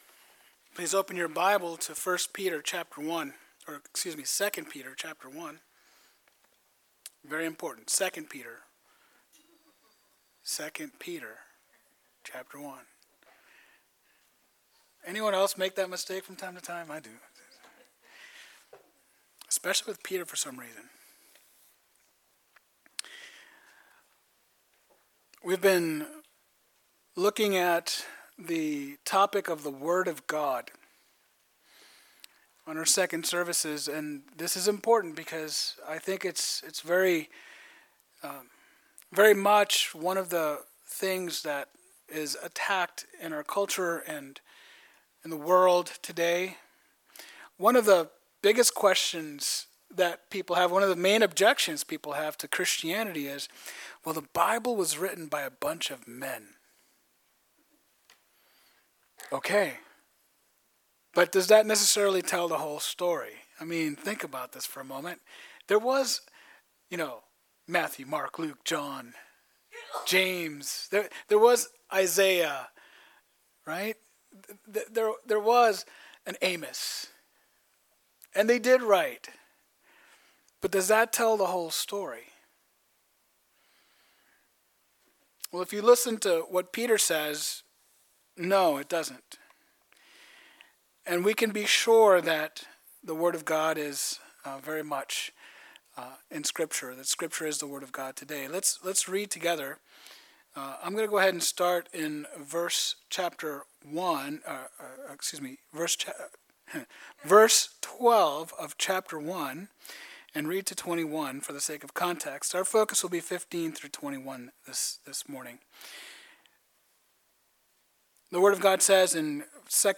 II Peter 1:15-21 Sunday 07/06/2025 2nd Service